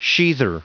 Prononciation du mot sheather en anglais (fichier audio)
Prononciation du mot : sheather